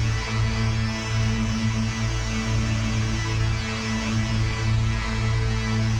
DD_LoopDrone3-A.wav